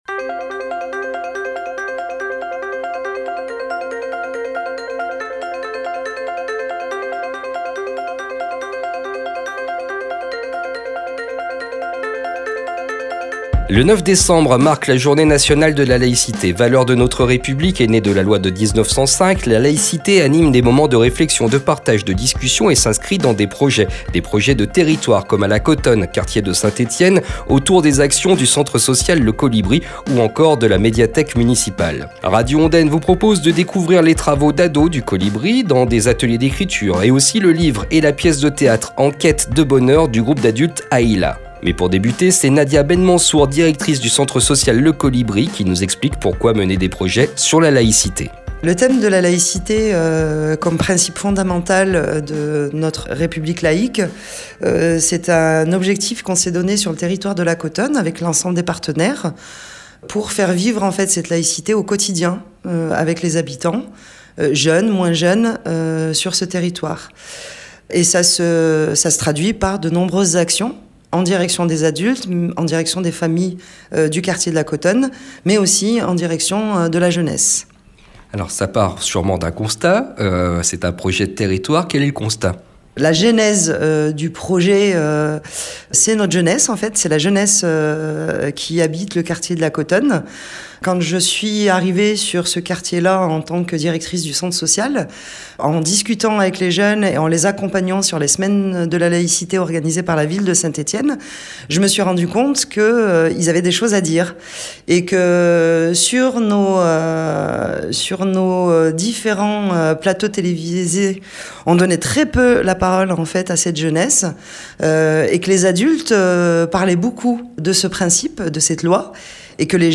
Reportage à écouter sur Radio Ondaine, aujourd’hui à 11h30, lundi 1er Décembre à 17h et durant la Journée Nationale de la Laïcité, mardi 09 Décembre.